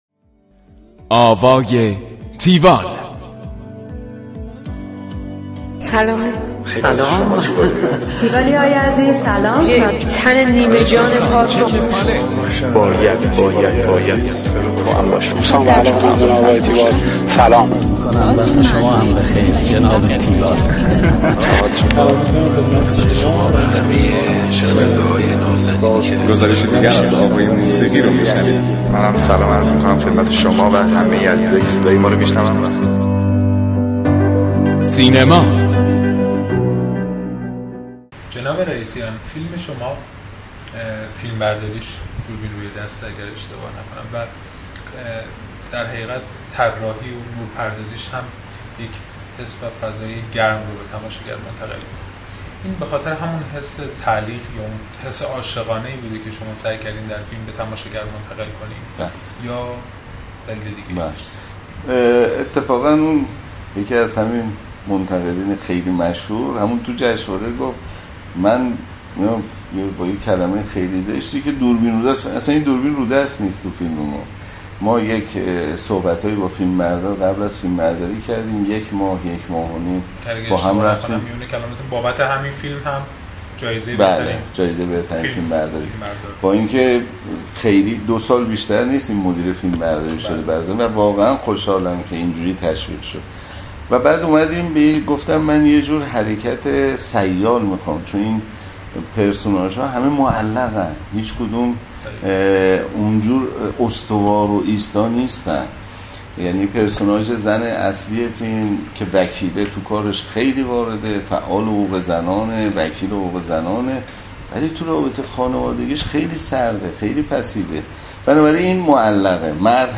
بخش دوم گفتگوی تیوال با علیرضا رئیسیان / کارگردان.
گفتگوی تیوال با علیرضا رئیسیان (بخش دوم)